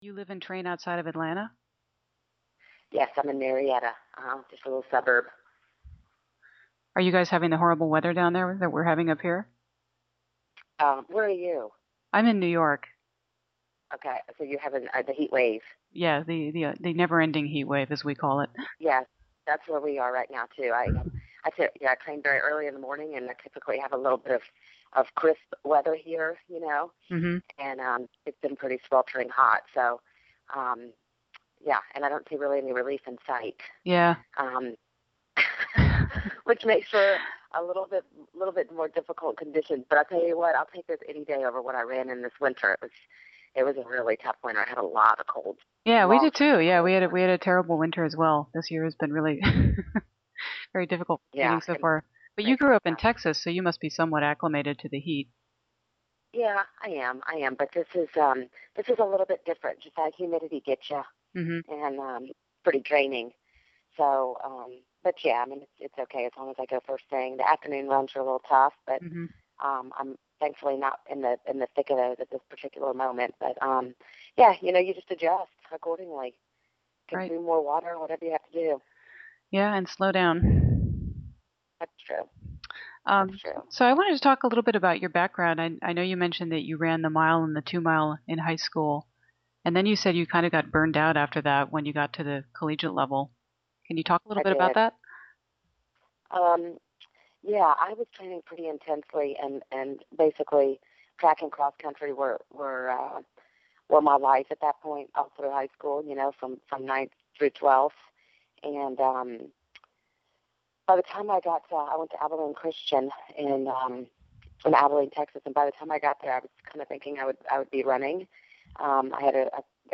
Also, my apologies for starting things off with mundane chitchat about the weather.